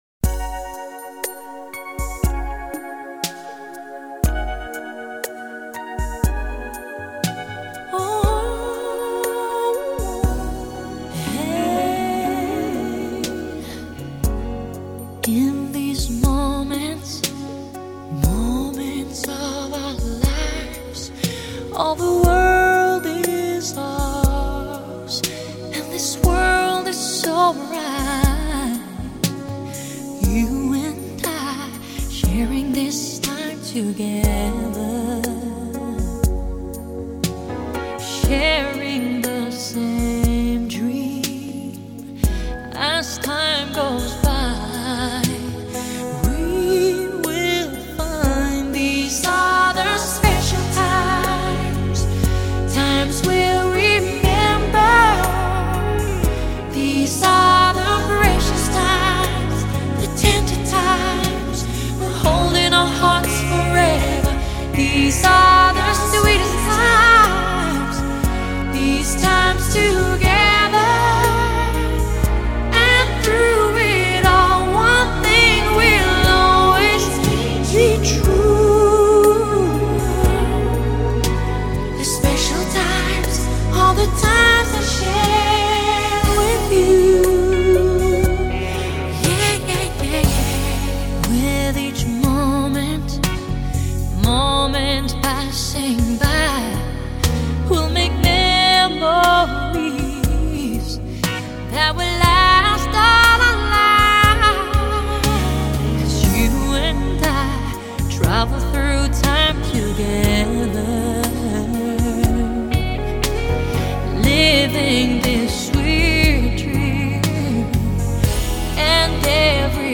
兼具流行情韵 圣洁气氛以及隽永歌声
圣诞歌曲。